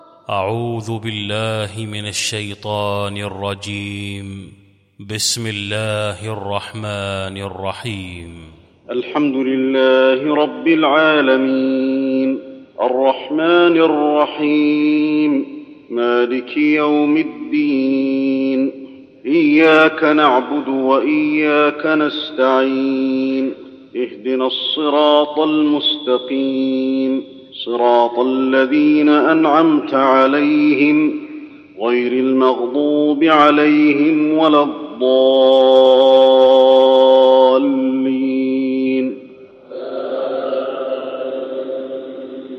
المكان: المسجد النبوي الفاتحة